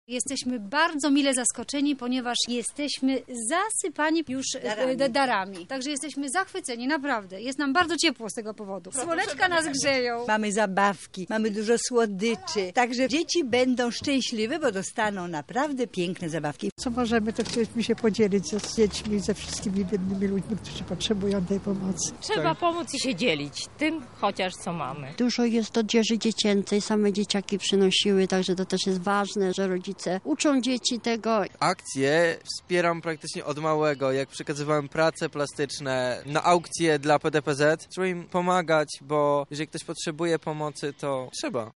W wydarzeniu uczestniczył nasz reporter.
sonda